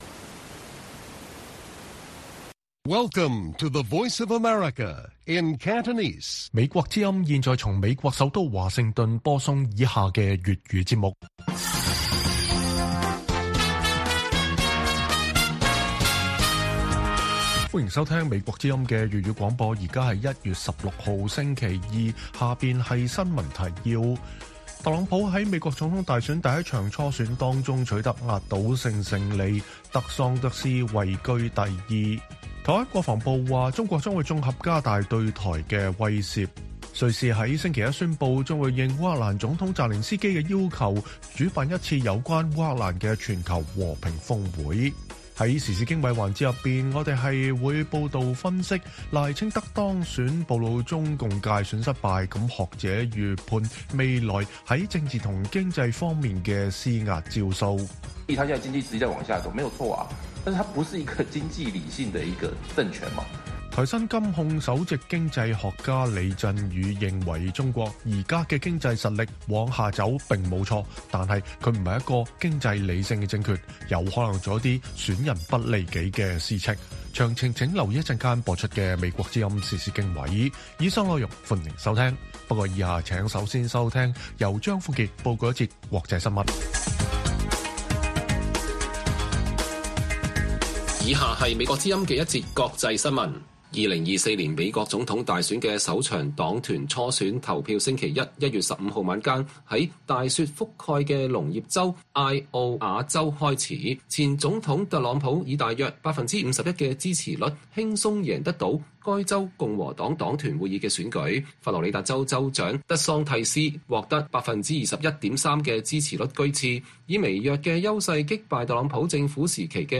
粵語新聞 晚上9-10點: 特朗普在美國總統大選首場初選中獲勝